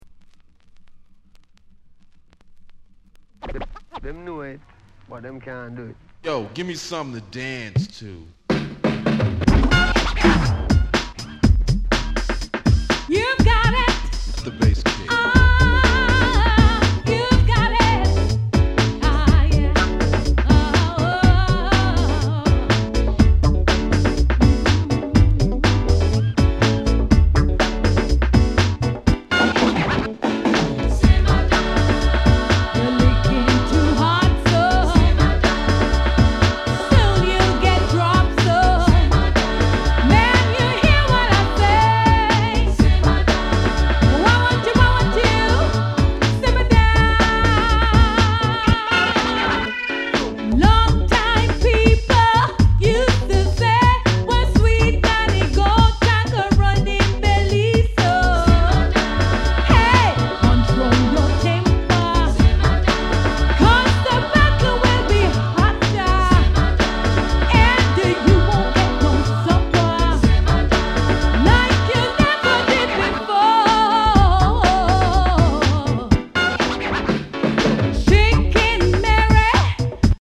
一瞬キナ臭いように感じてしまいそうですが、これはこれで良いグッドグルーヴ。イナたい展開も逆に新鮮です。